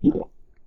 gulp.ogg